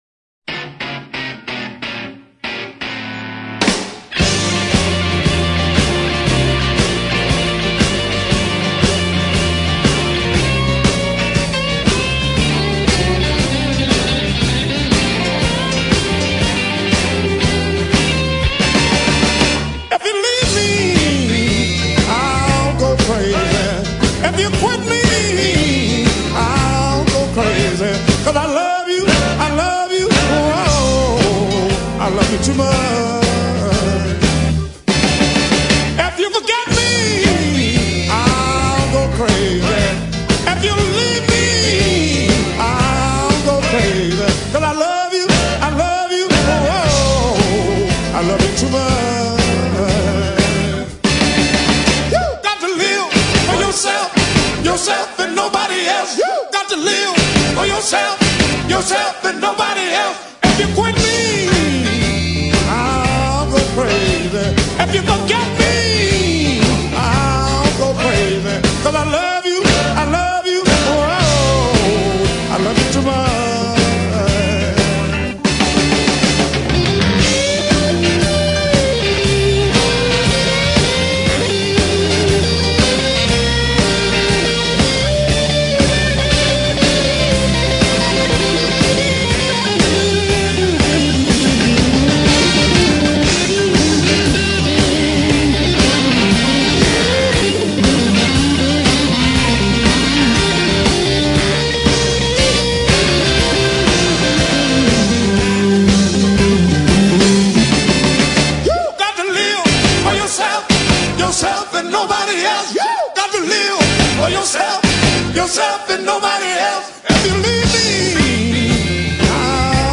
Jazz Blues Para Ouvir: Clik na Musica.